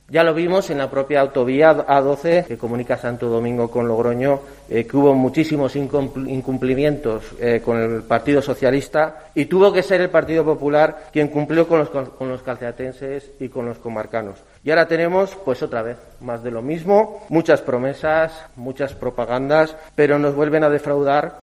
David Mena, alcalde de Santo Domingo de la Calzada